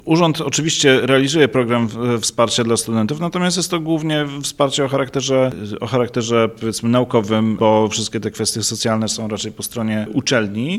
Czy Urząd Miasta Wrocławia planuje we współpracy z uczelniami działania, które mogłyby wspomóc sytuację studentów? Zapytaliśmy o to Piotra Uhle, radnego Rady Miejskiej Wrocławia.